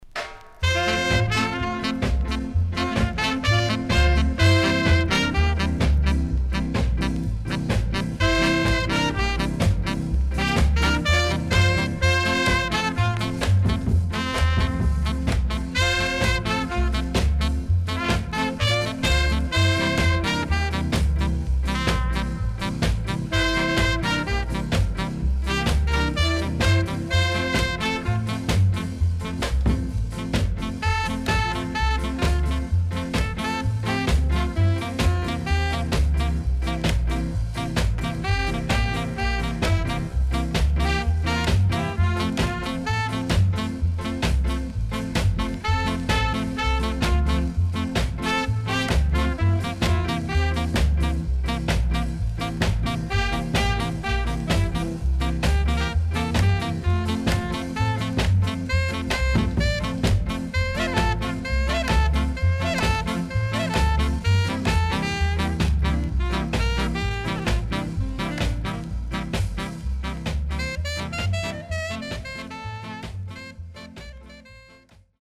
Good Rocksteady & Ska Inst.W-Side Good
SIDE A:曇りによるノイズ入ります。